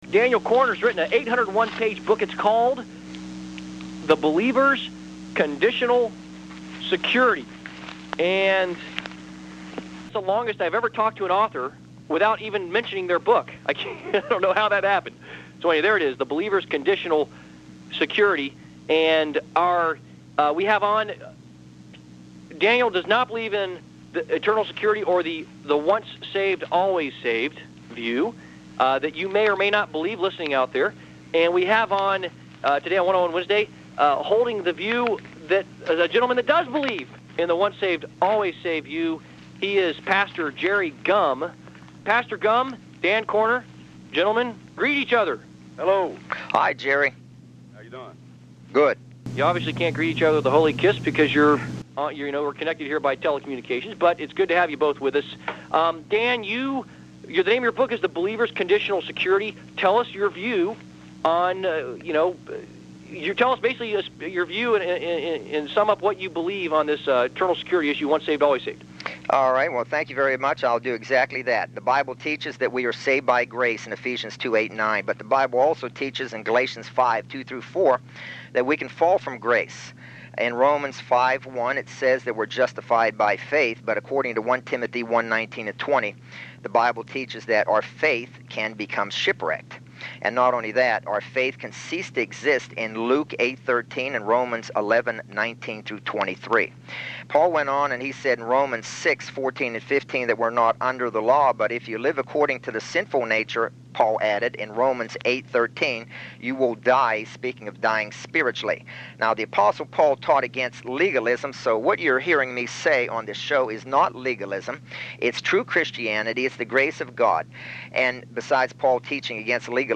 Eternal Security Radio Interview